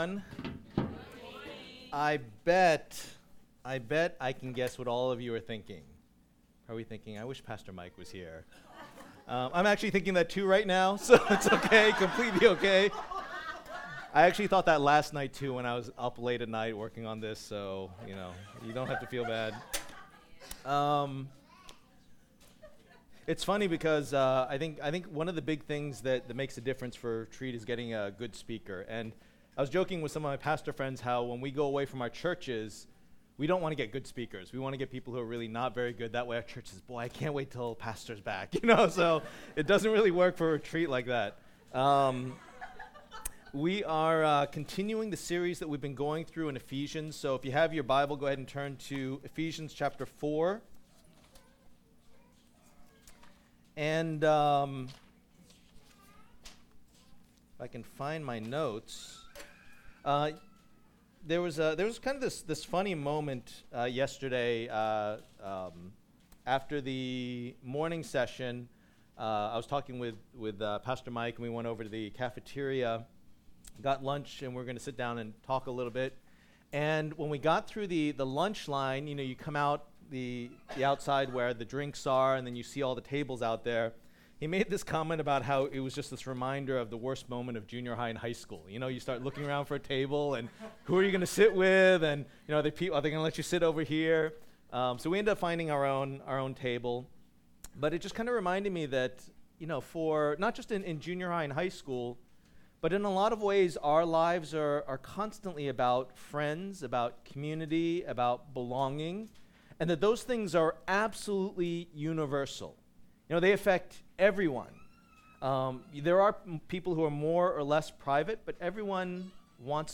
Sunday Service - Walking in Unity.mp3